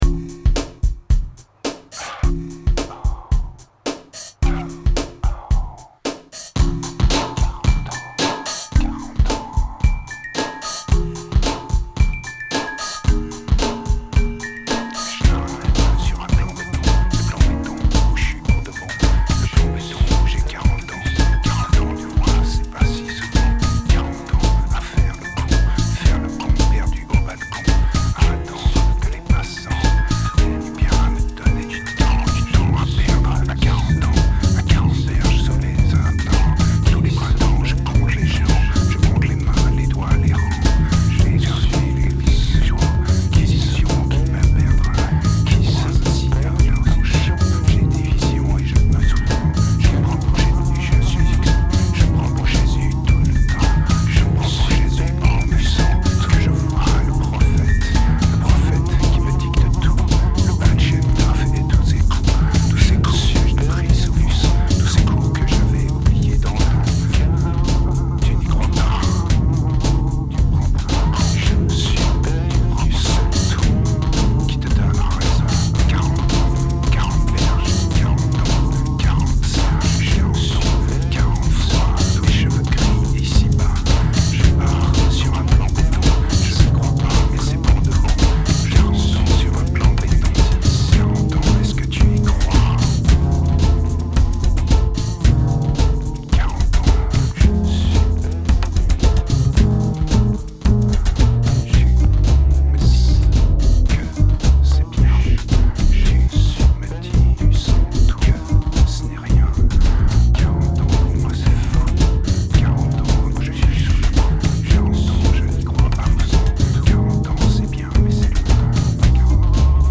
turned out as yet another dirty electro release.